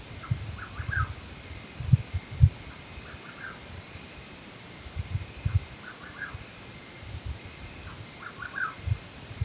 Atajacaminos Colorado (Antrostomus rufus)
Nombre en inglés: Rufous Nightjar
Condición: Silvestre
Certeza: Vocalización Grabada